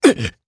Lusikiel-Vox_Damage_jp_02.wav